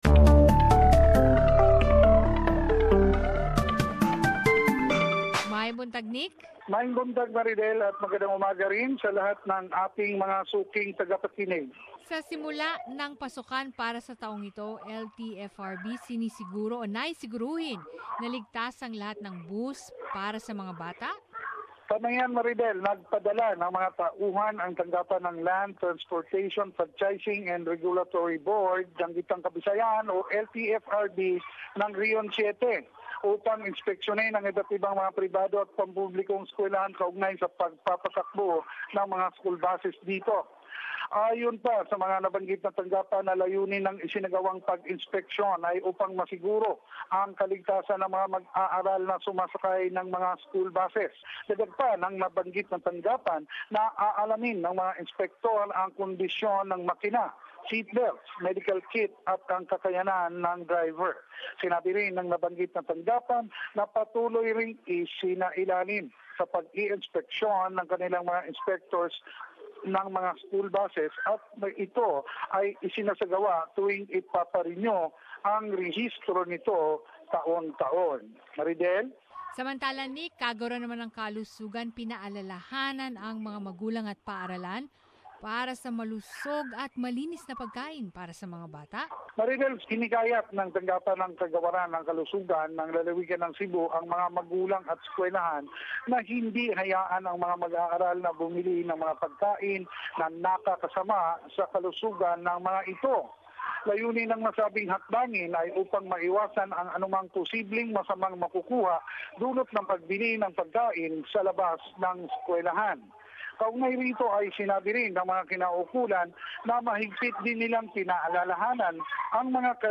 Balitang Bisayas. Summary of the latest news in the Visayas region